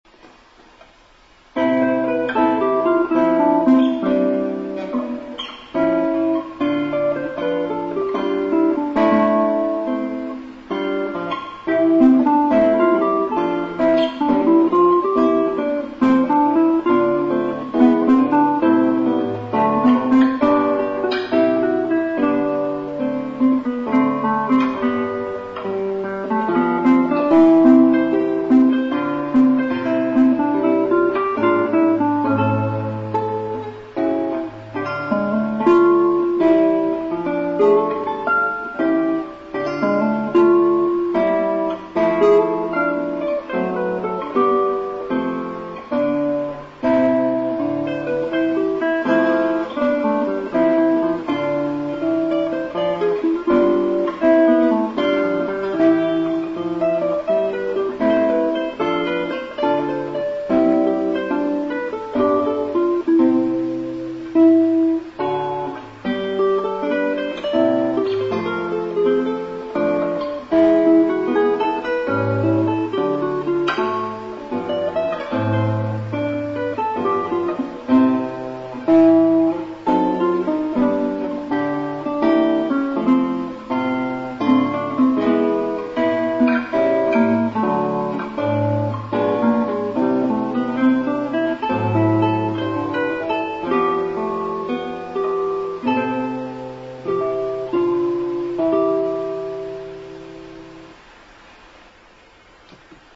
エチュードNo1 / ひとり二重奏